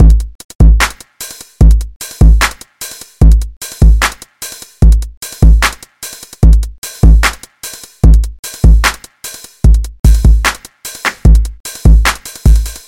标签： 149 bpm Hip Hop Loops Drum Loops 2.17 MB wav Key : Unknown
声道立体声